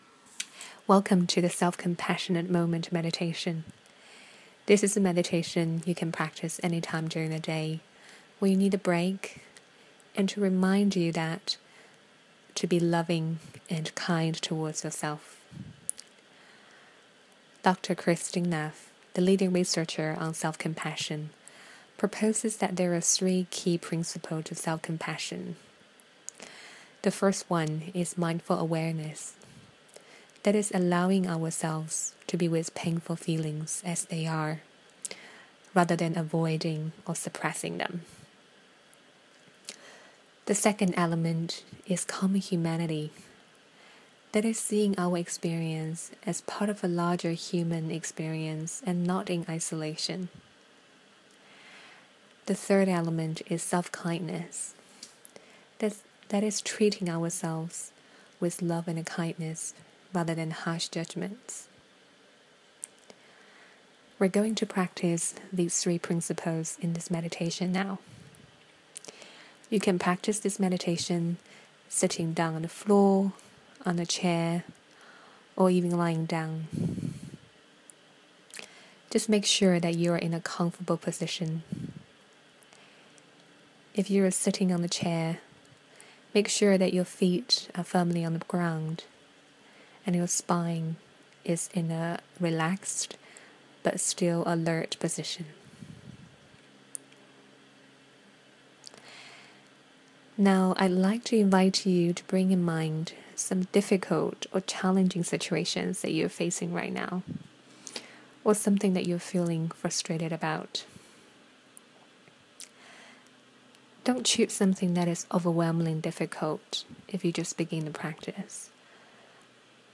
Guided meditation
3. Self-compassionate moment meditation